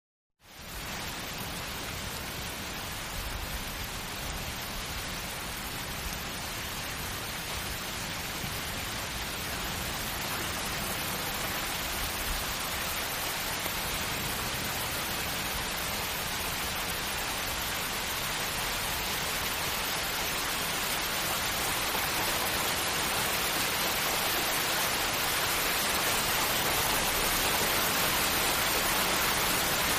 Heavy Rain
Heavy Rain is a free ambient sound effect available for download in MP3 format.
# rain # weather # loop About this sound Heavy Rain is a free ambient sound effect available for download in MP3 format.
005_heavy_rain.mp3